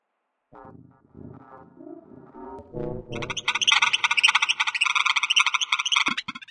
描述：用奥林巴斯DS40和索尼ECMDS70P走过迈阿密都市动物园。走过动物园，寻找恐龙展品。
标签： 动物 现场记录 动物园
声道立体声